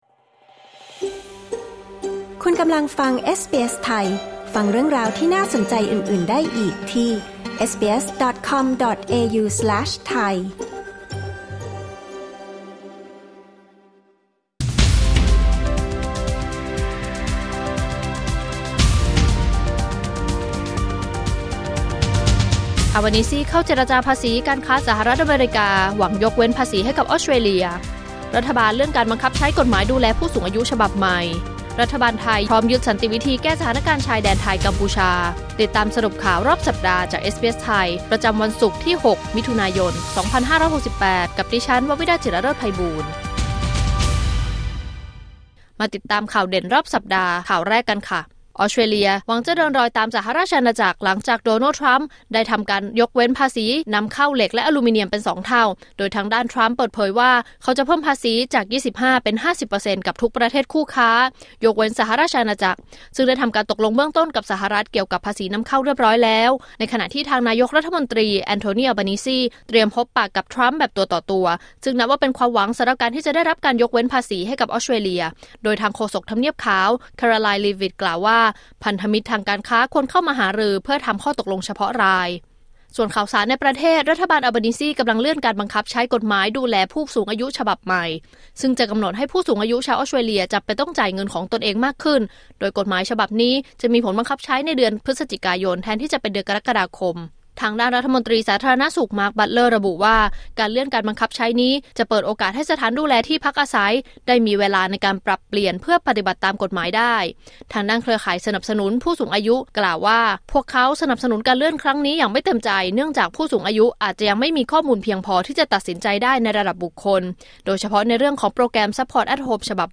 สรุปข่าวรอบสัปดาห์ 6 มิถุนายน 2568